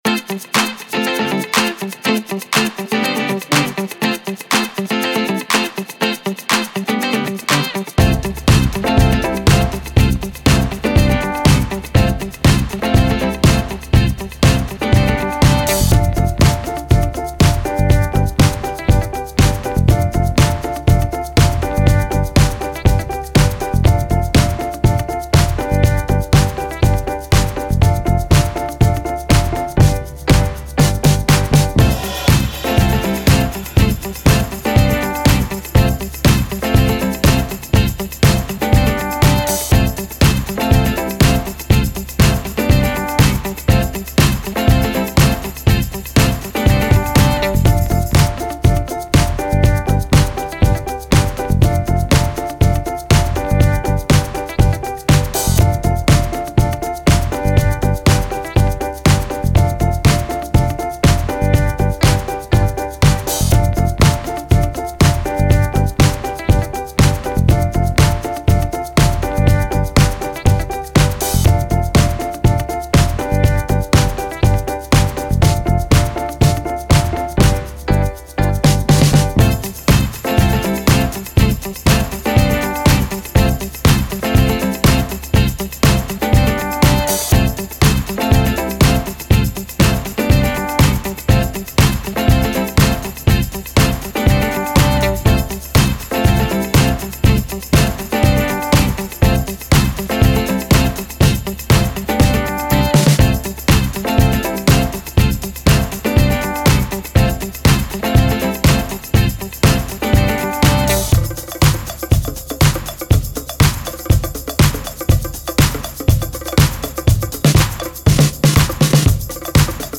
3. Позитивная и вдохновляющая